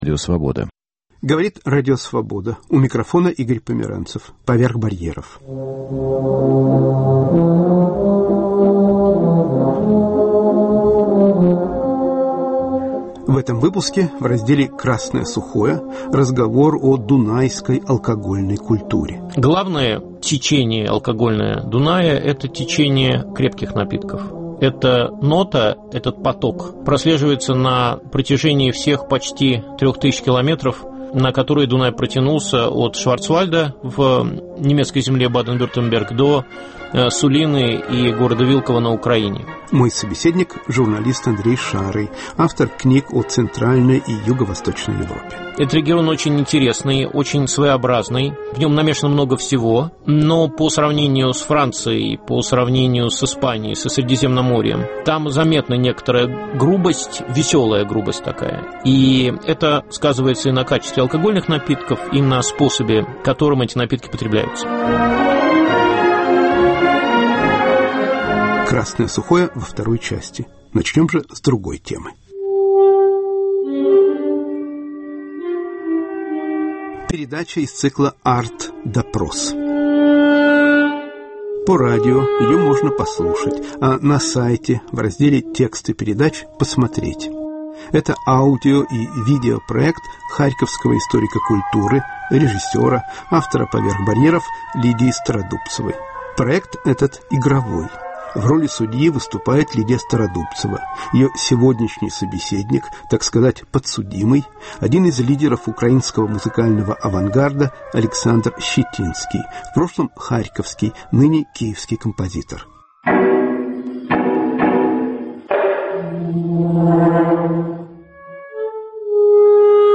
"Арт-допрос": разговор